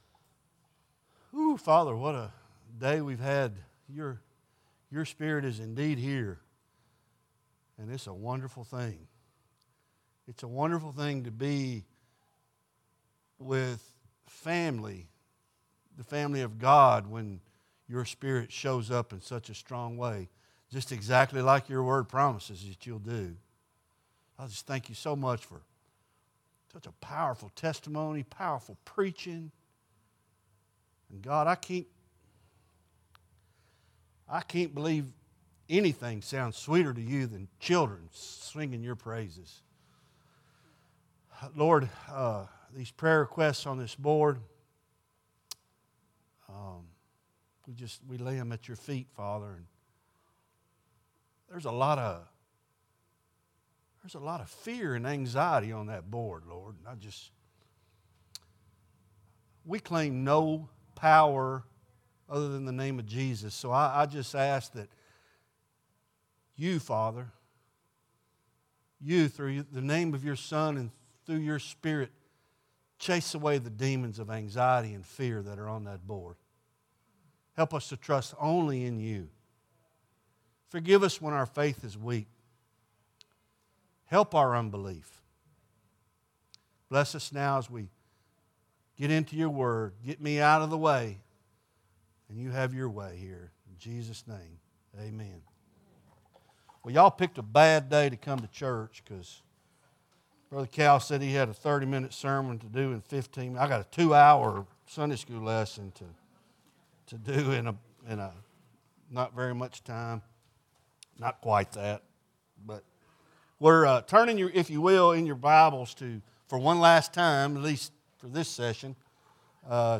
Sermons Archive - Page 108 of 311 - 2nd Mile Church